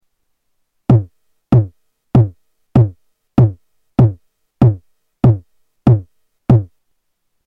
AirBase 99 bassdrum 2
Category: Sound FX   Right: Personal
Tags: Sound Effects JoMoX Sounds JoMoX XBase AirBase